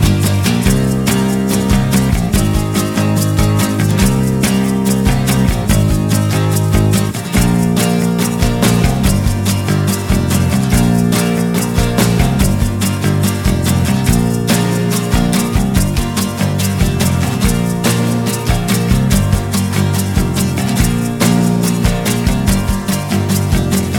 Minus Acoustic Guitar Pop (1980s) 3:31 Buy £1.50